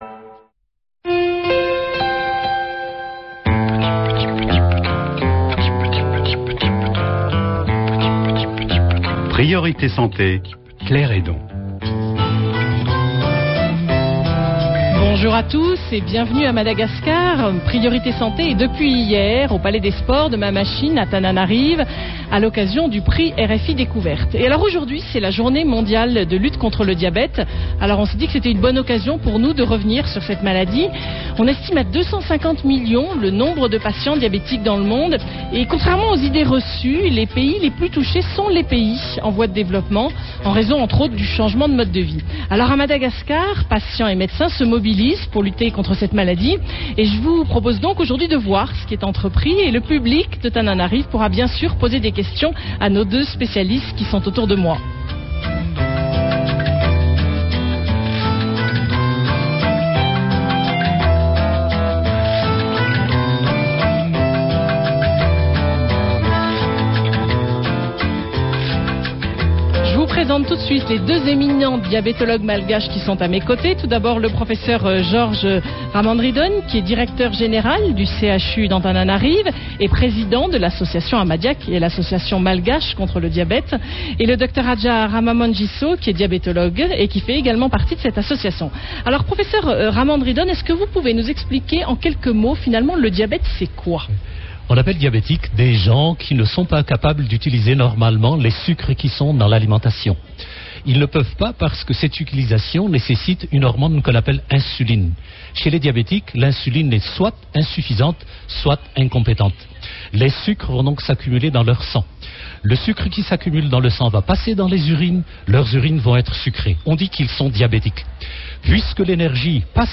Emission enregistrée en public à Madagascar Journée mondiale contre le diabète